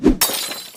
item_breaks.ogg